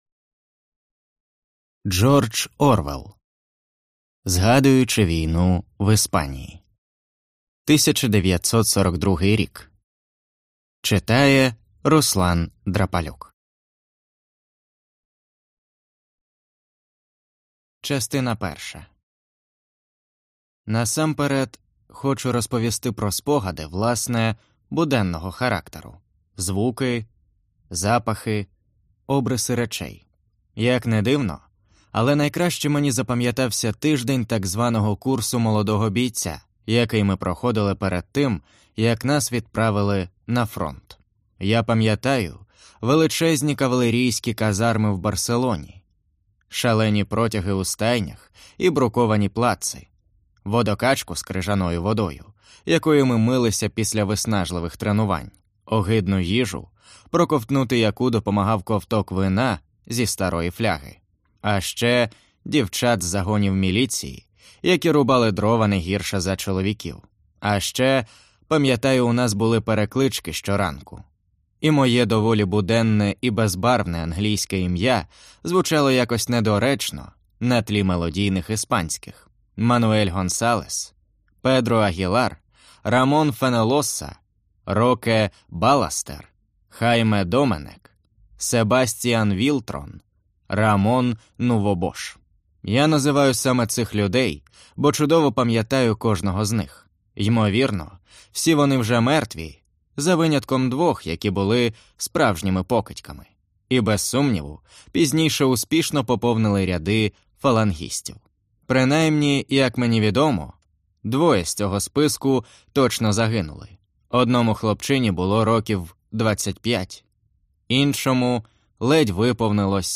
Аудиокнига Згадуючи війну в Іспанії | Библиотека аудиокниг